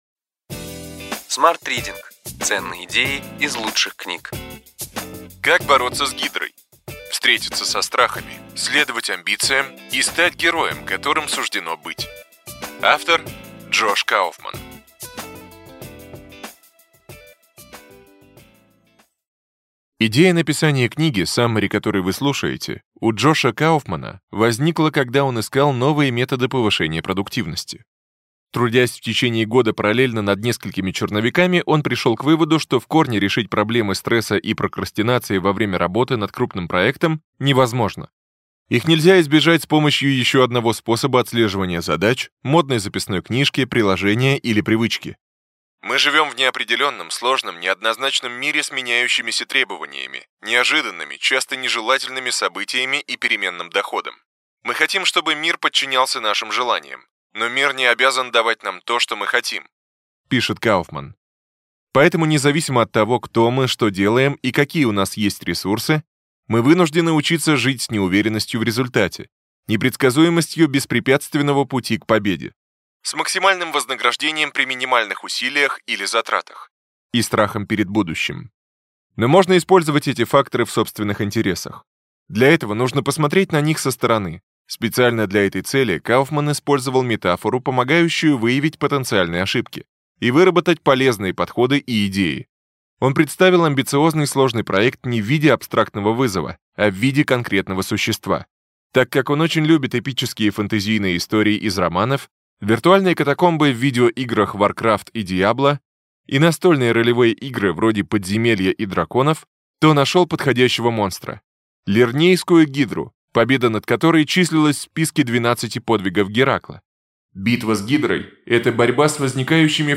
Аудиокнига Ключевые идеи книги: Как бороться с гидрой: встретиться со страхами, следовать амбициям и стать героем, которым суждено быть.